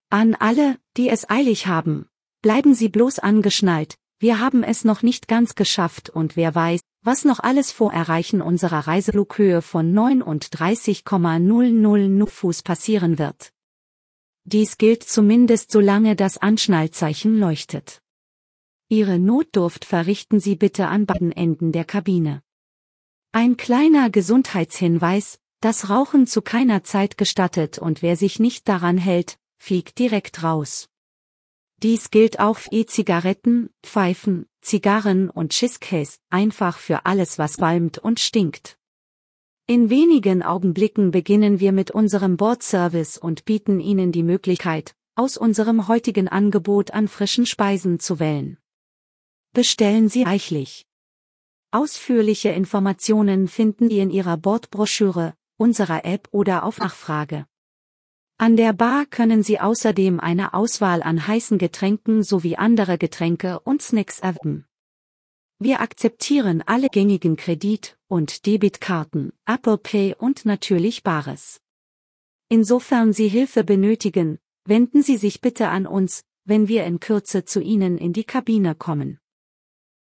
AfterTakeoff.ogg